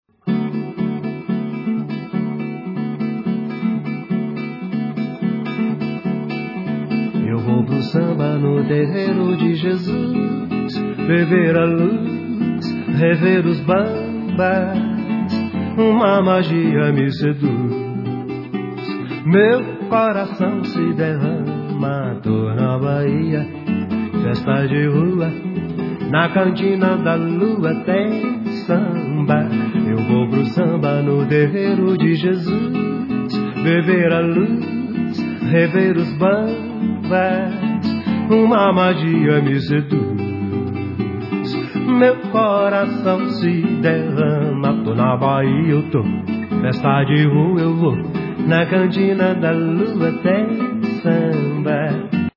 Guitarist, Composer, Lyricist